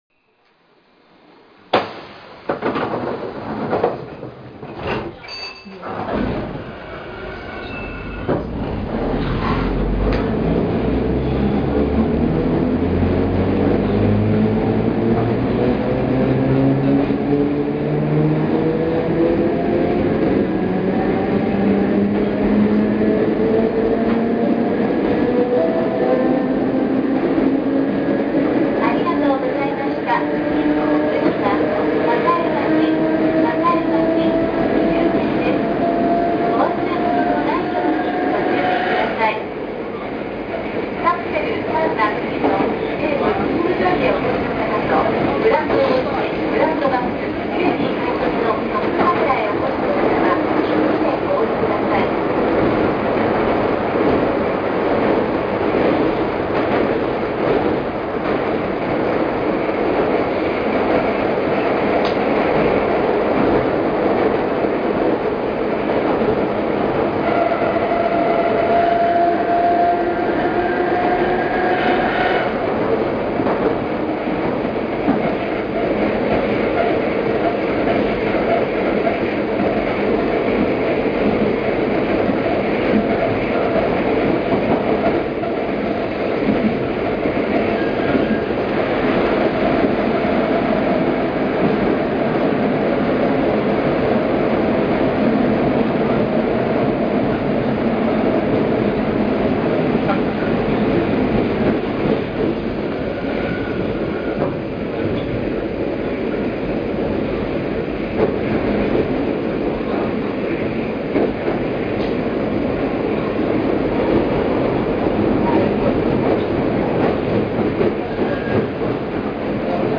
地下区間も優等列車もある大手私鉄の路線で吊り掛け式の車両が2011年まで運行されていた、というのはそれだけでとんでもない事なのですが、2次車に関しては見た目は完全に平成の車両である為、吊り掛けのモーター音とのミスマッチがとても趣味的に面白い車両でした。
6750系走行音（収録は全て6754Fにて）
【瀬戸線】東大手〜栄町（2分43秒：1.24MB）
瀬戸線の車両は全て車内自動放送を完備していますが、6750系の車内ではその放送はほとんど聞こえません。
6750_HigashiOte-Sakaemachi.mp3